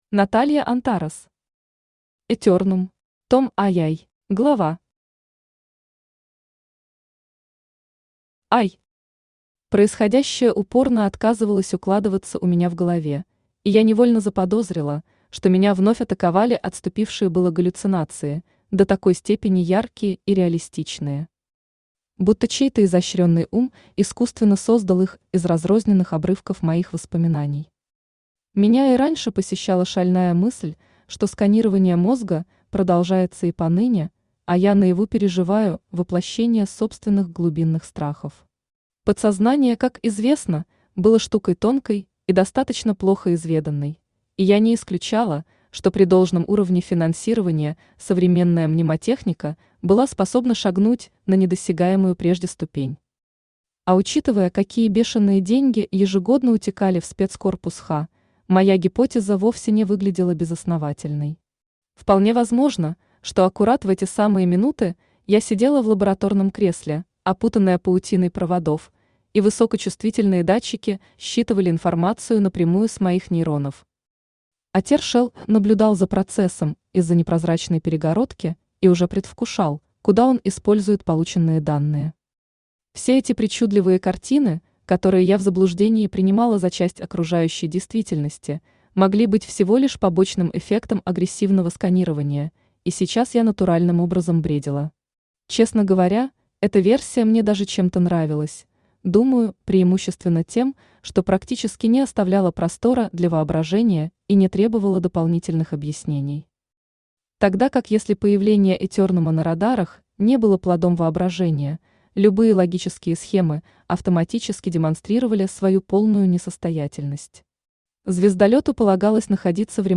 Аудиокнига «Этернум». Том II | Библиотека аудиокниг
Том II Автор Наталья Антарес Читает аудиокнигу Авточтец ЛитРес.